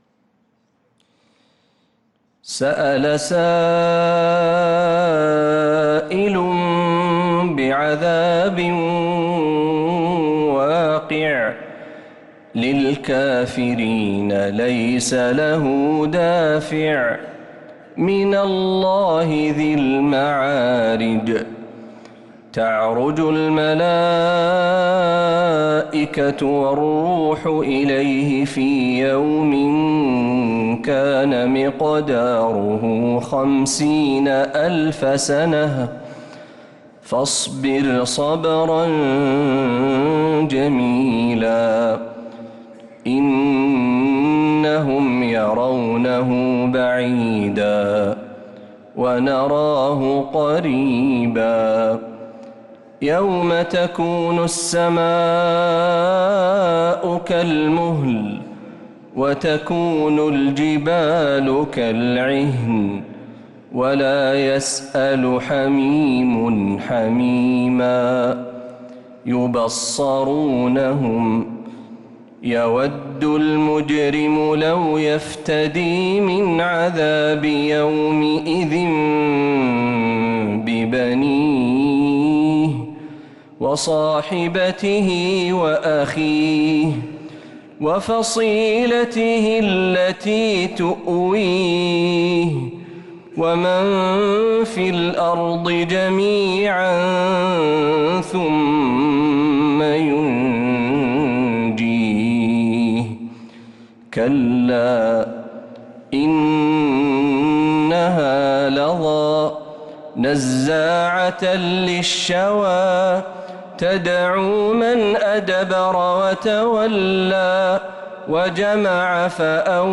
سورة المعارج كاملة من عشائيات الحرم النبوي